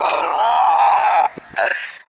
Five Nights At Rocky's Jumpscare Bouton sonore